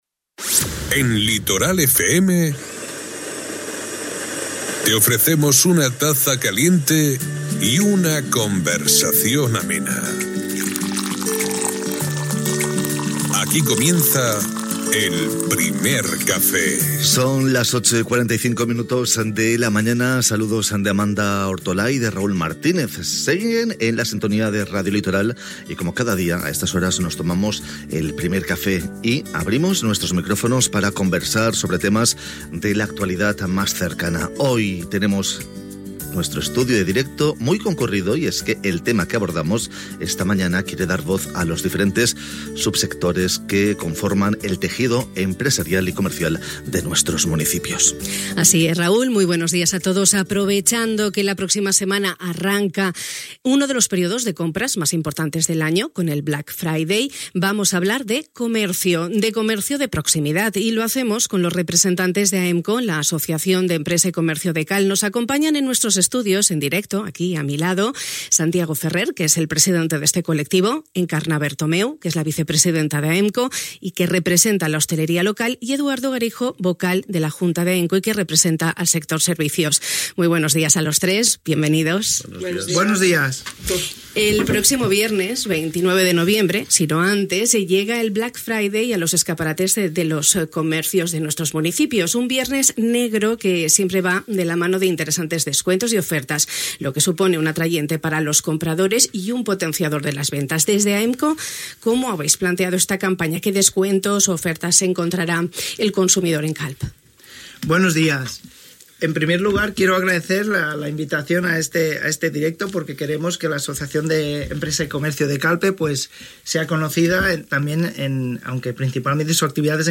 Fugi al Primer Cafè de Ràdio Litoral hem aprofitat la celebració del Black Friday, una jornada comercial que sempre a dalt de la mà d'interessants descomptes i ofertes per al consumidor, per parlar de comerç, d'estratègies de màrqueting i de les expectatives de vendes que té el sector empresarial de cara a les properes setmanas. I ho hem fet amb representants d'AEMCO, l'Associació d'Empresa i Comerç de Calp.